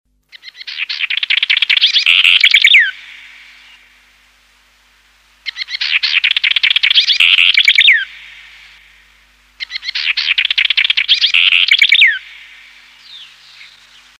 Chercán – Aprende con Ciencia
Se le identifica fácilmente por su cabeza parda con rayas, pero sobre todo por su cola que lleva levantada. A pesar de su diminuto tamaño, tiene un canto muy fuerte y potente, descrito como un trino rápido y variado que culmina en una nota acentuada.
Troglodytes-Aedon-Chilensis-1-Chercan.mp3